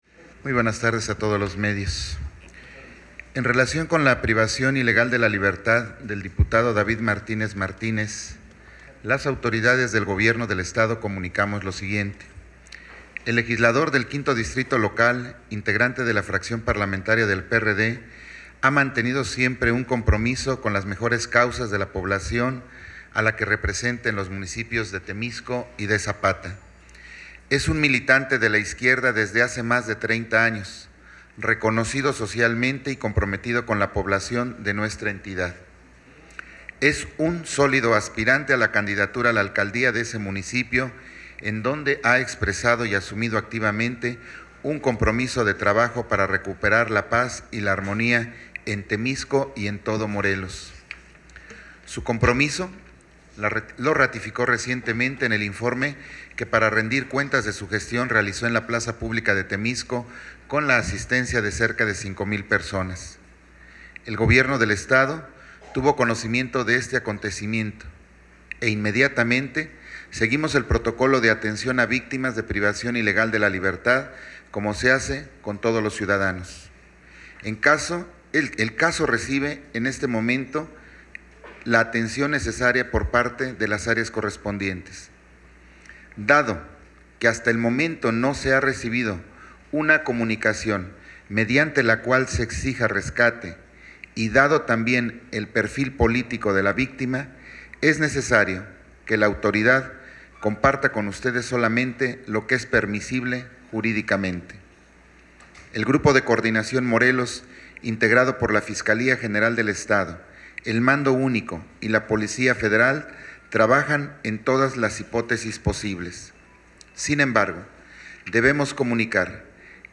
COMUNICADO OFICIAL DADO A CONOCER POR SECRETARIO DE GOBIERNO MATÍAS QUIROZ MEDINA